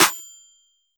Goose Snare.wav